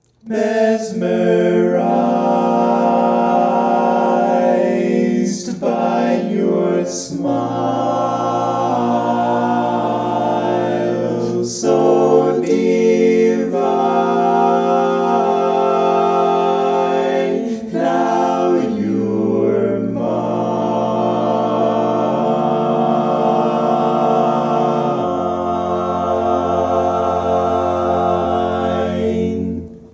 Key written in: F Major
How many parts: 4
Type: Barbershop
All Parts mix:
Learning tracks sung by